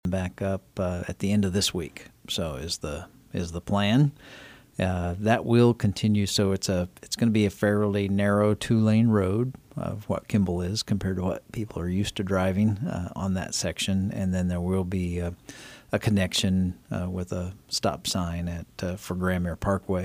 Manhattan City Manager Ron Fehr says paving of the temporary access roads are expected to be completed sometime this week.